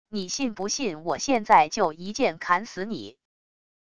你信不信我现在就一剑砍死你wav音频生成系统WAV Audio Player